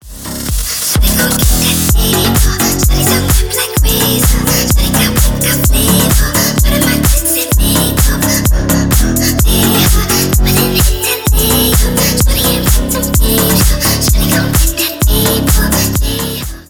• Качество: 320 kbps, Stereo
Ремикс
громкие